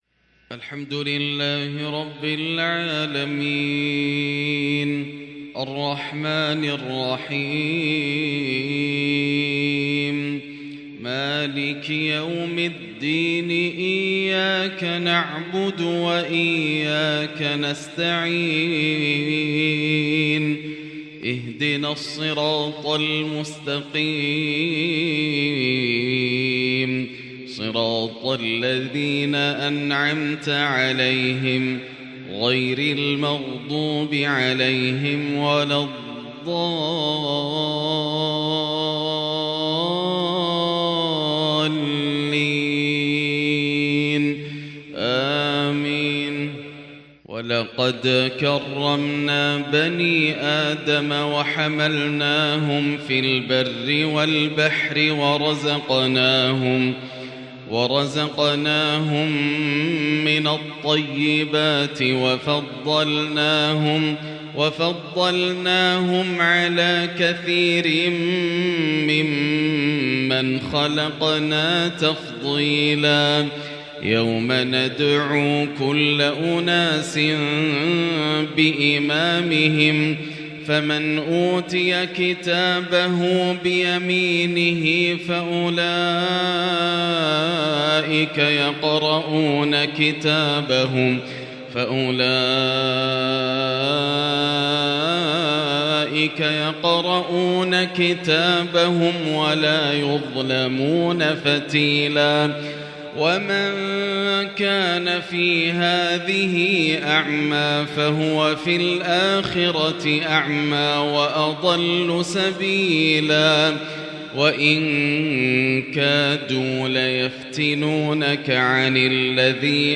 “لقد كان في يوسف وإخوته” تجليات مذهلة لتلاوات نادرة للغريد الآسر د.ياسر الدوسري > مقتطفات من روائع التلاوات > مزامير الفرقان > المزيد - تلاوات الحرمين